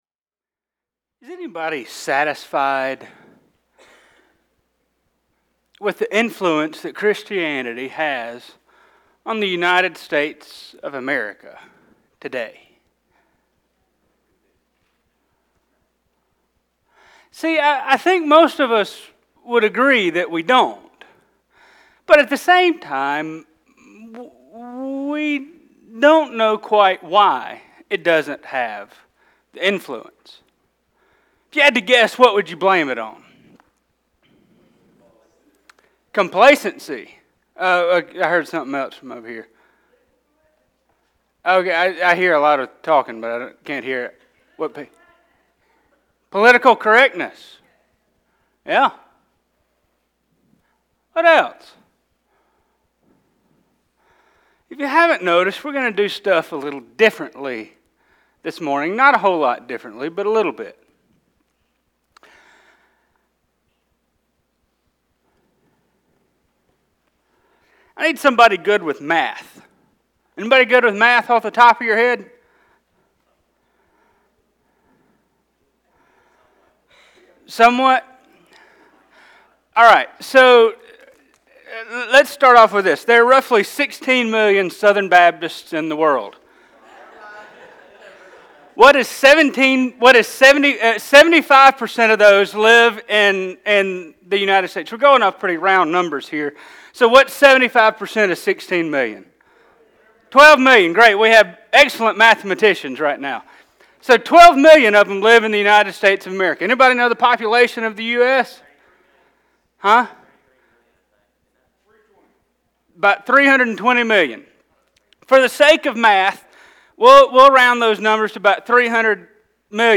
Sermons | OCILLA BAPTIST CHURCH